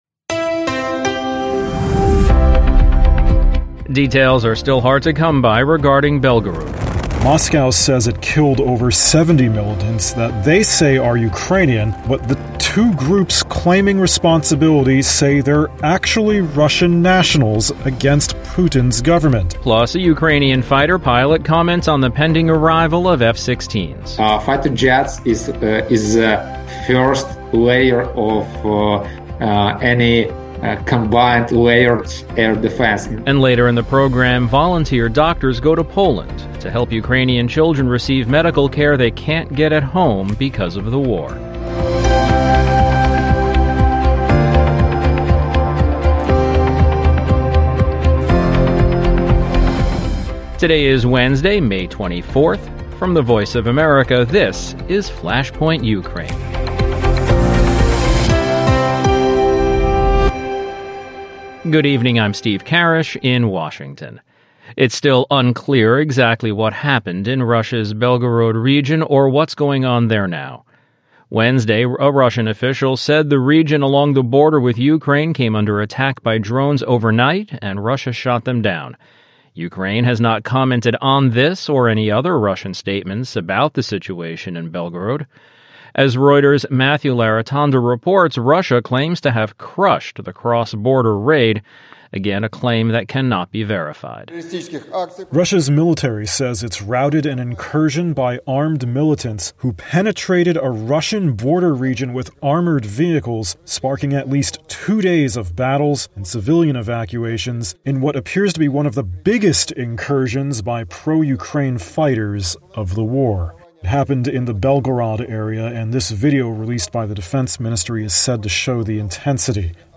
As more details come to light from Belgorod, we are left with more questions. A conversation with a Ukrainian jet pilot and a look at The Wagner Group’s next move after Bakhmut. Plus, Ukrainian children are taken to Poland to get medical care they can’t get at home because of the war.